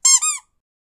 squeak.wav